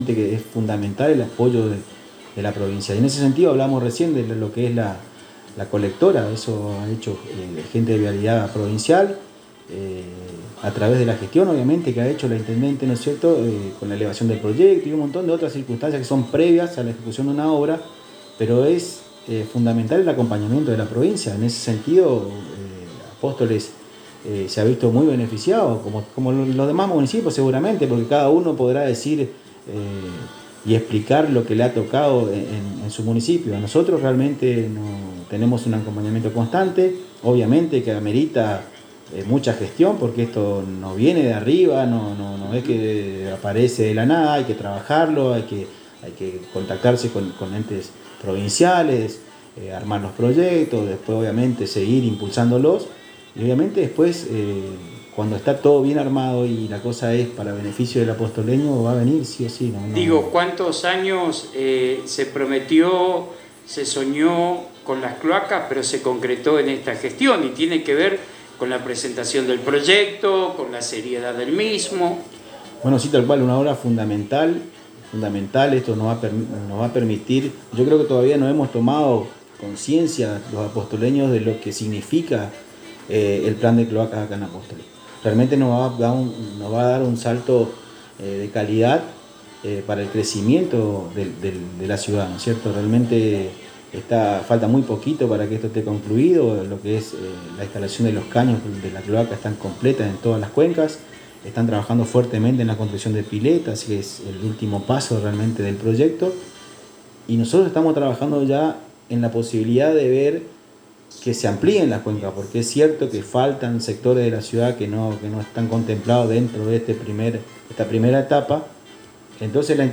El Secretario de Obras Públicas de la Municipalidad de Apóstoles en entrevista exclusiva dada a la ANG expresó que en esta gestión de María Eugenia Safrán que inició en el 2019 ha realizado obras en todos los barrios de la Ciudad y en el Centro por igual.